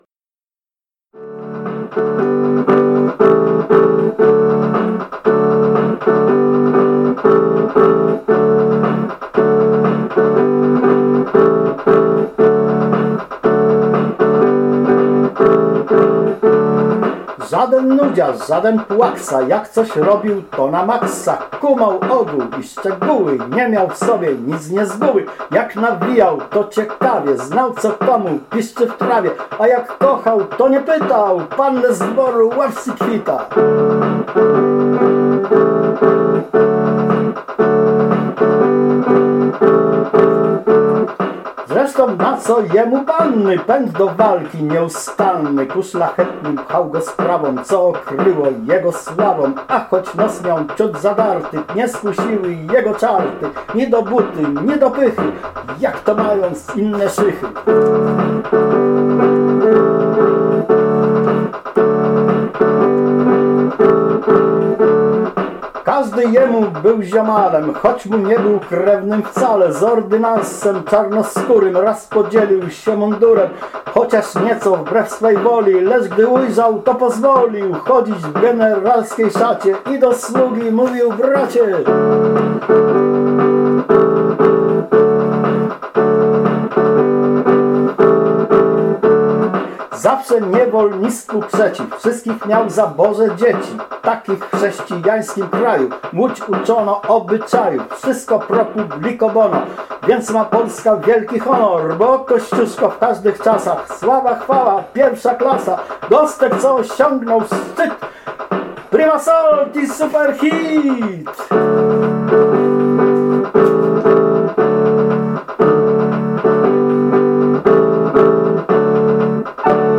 A tymczasem posłuchajmy piosenki-rapu "Idol młodzieży", w której przewija się motyw przyjaźni Kościuszki z jego czarnoskórym przyjacielem, ordynansem Agryppą Hull.
Rap Idol młodzieży